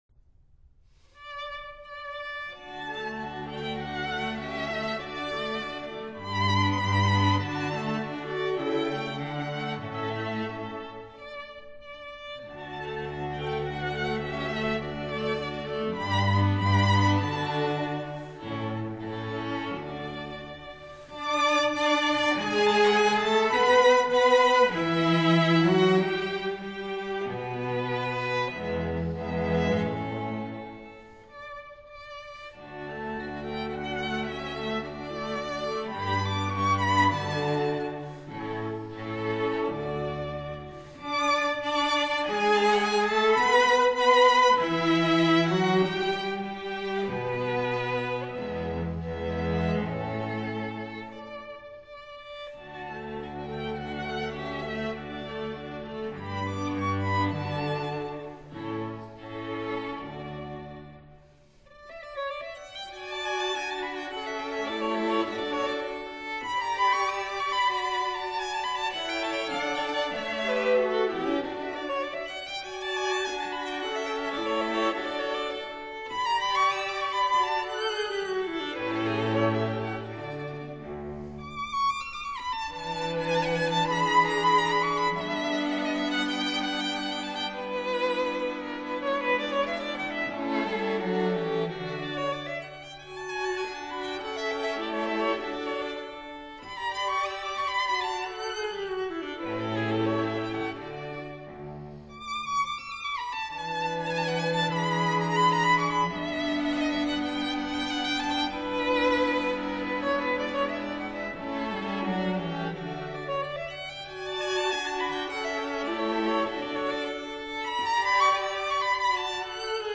viola
cello